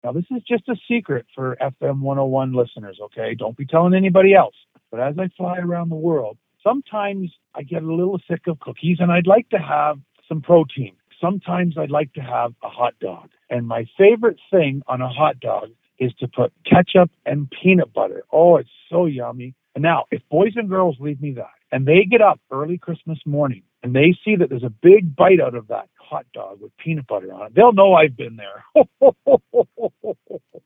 How many kids in Milton are on the naughty list? Santa tells all in exclusive interview
FM 101 Milton thank Santa Claus for his generosity in taking our call and hope he has a safe flight.